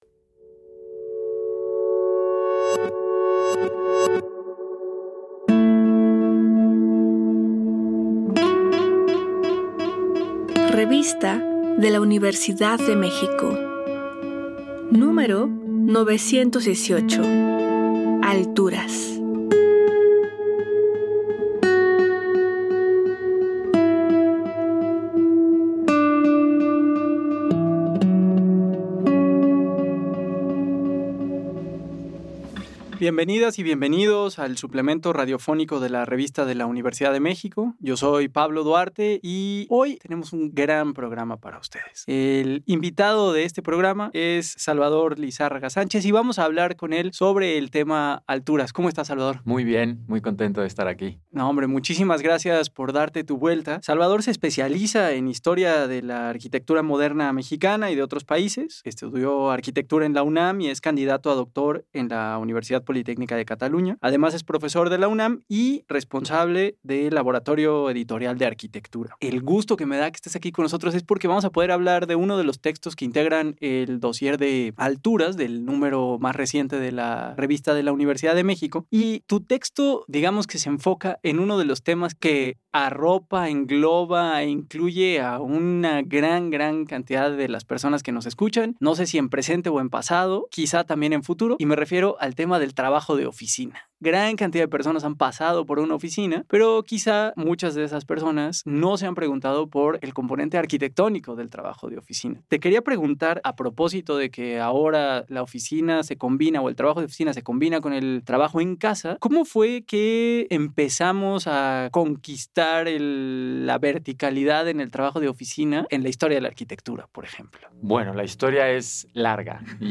Primera parte de la conversación sobre la arquitectura vertical y sus vínculos con el trabajo. Gracias a las innovaciones de materiales y técnicas de construcción las ciudades conquistaron las alturas y pronto los edificios se volvieron nuevos espacios de trabajo que determinaron la evolución del mundo laboral urbano.